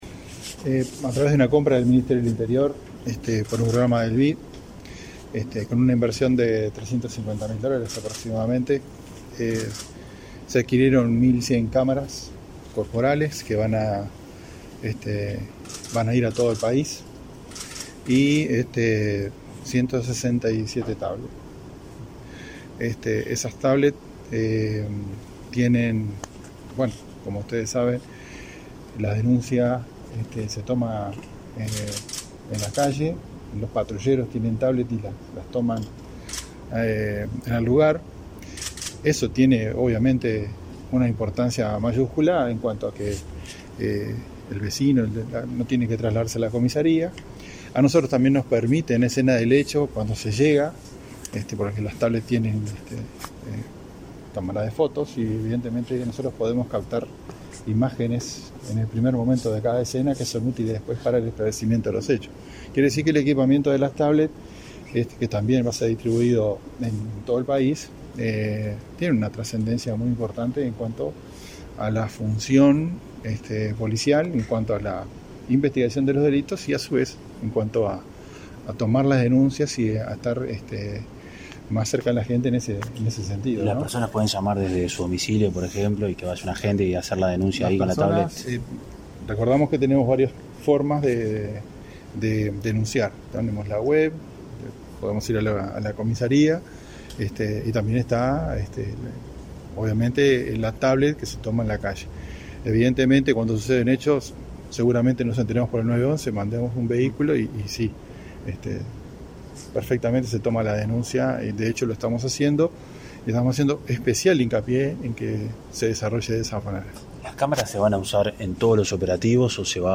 Declaraciones a la prensa del jefe de la Jefatura de Policía de Montevideo, Mario D’Elía
Declaraciones a la prensa del jefe de la Jefatura de Policía de Montevideo, Mario D’Elía 01/02/2023 Compartir Facebook X Copiar enlace WhatsApp LinkedIn Autoridades del Ministerio del Interior, encabezadas por su ministro, Luis Alberto Heber, presentaron, este 1.° de febrero, una nueva partida de equipamiento para uso de los efectivos policiales. Tras el evento, el jefe de la Jefatura de Policía de Montevideo, Mario D’Elía, realizó declaraciones a la prensa.